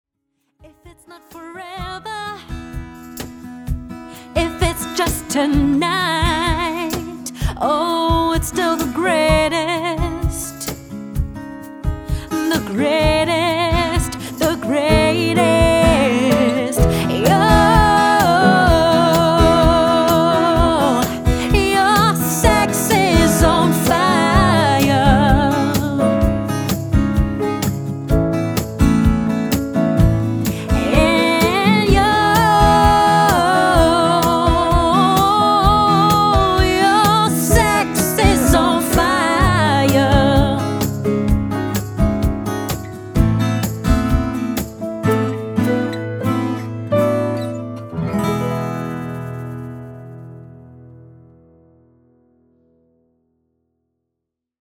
Vip Lounge Music